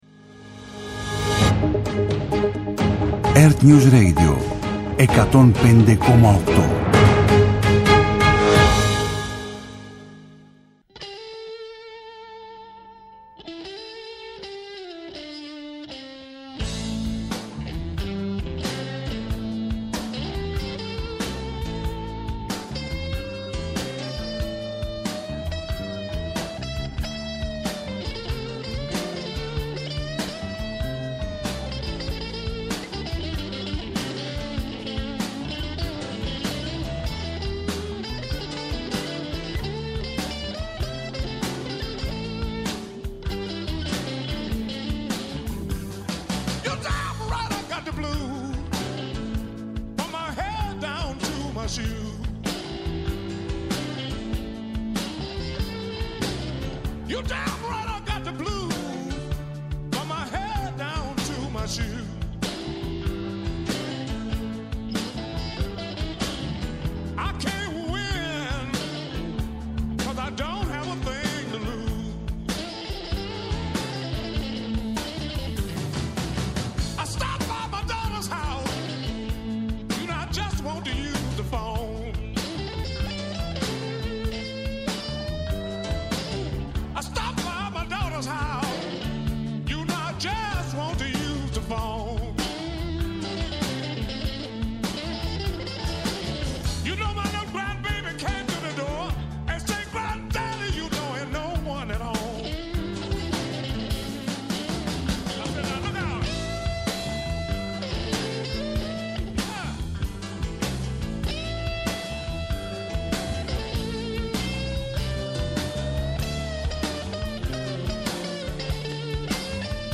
Απόψε στο studio